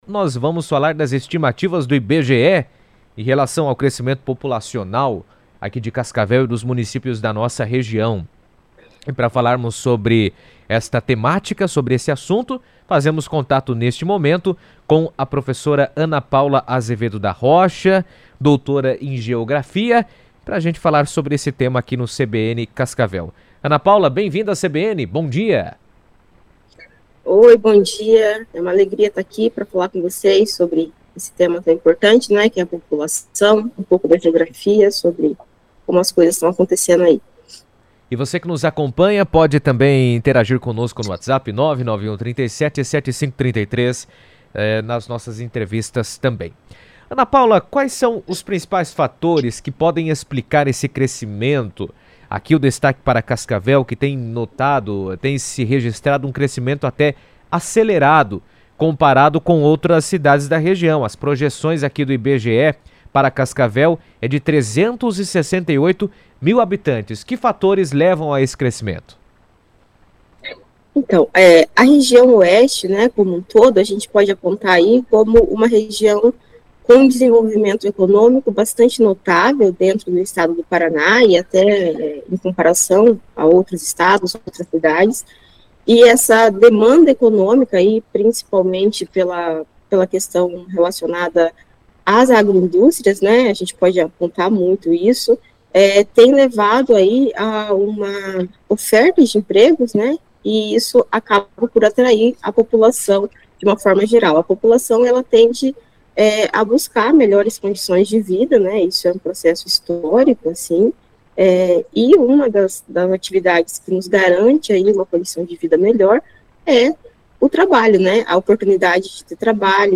Cascavel lidera o crescimento populacional no Oeste do Paraná, de acordo com as estimativas mais recentes do IBGE, que apontam avanço acima da média regional e reforçam a importância do município como polo de desenvolvimento. Em entrevista à CBN Cascavel